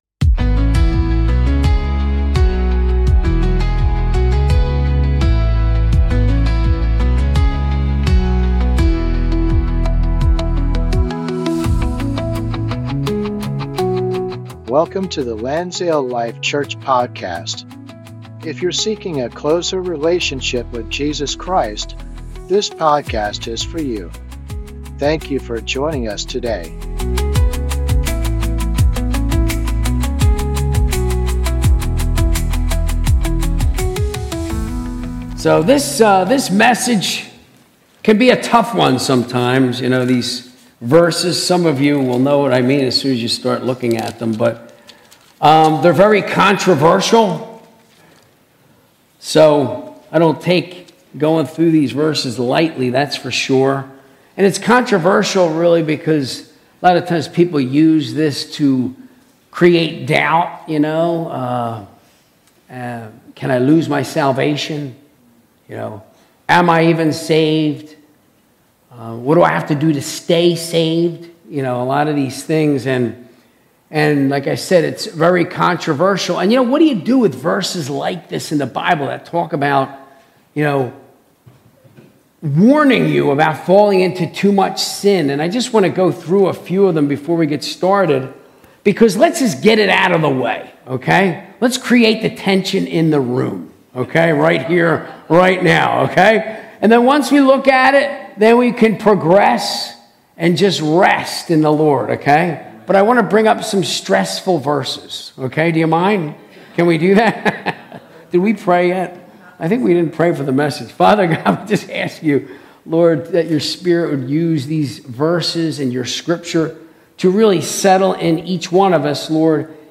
Sunday Service - 2025-08-25